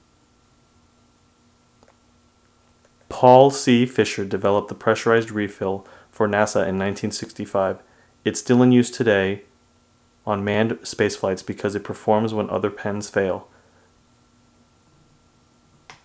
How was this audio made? I now have a noise floor between -51 & -48, but there are crickets going crazy outside so that was an unforeseen issue. Here is a sound check and reading, no effects, with just a laptop and the mixer. The red marks are where the digital system overloaded and created distortion.